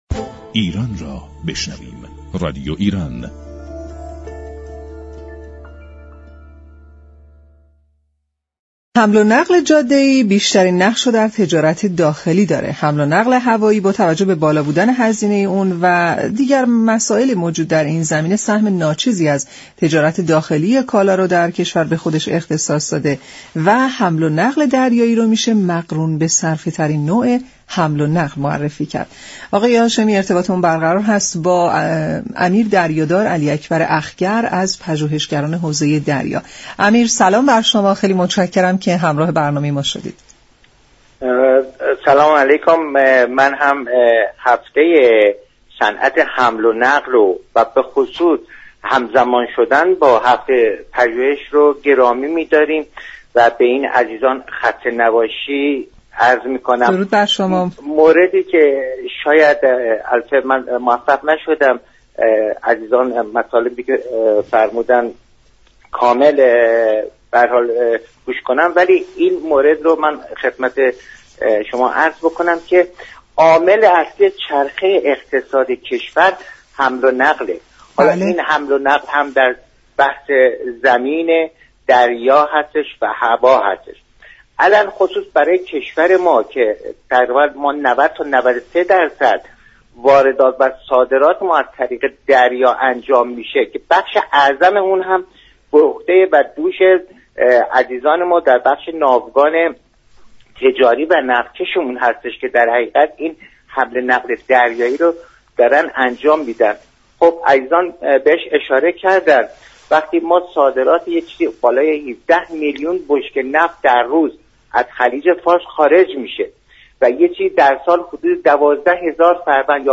از پژوهشگران حوزه دریا در گفت و گو با برنامه نمودار